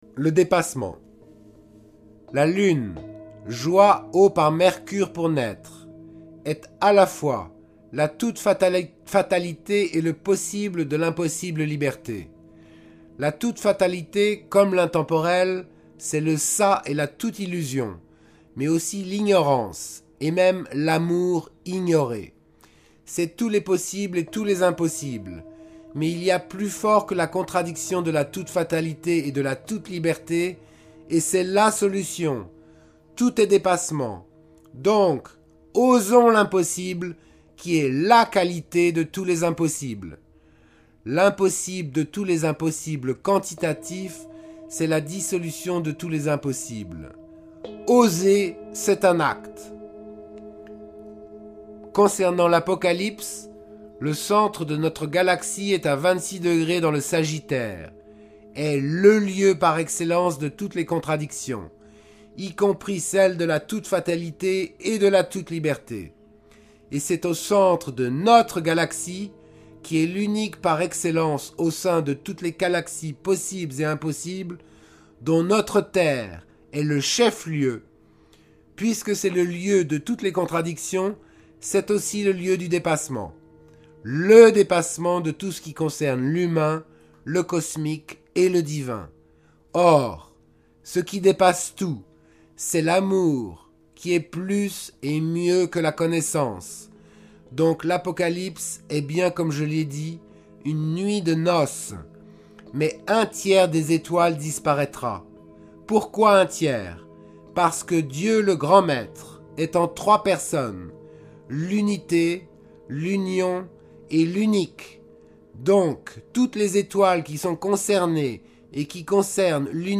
Lecture et jeux de bols sept métaux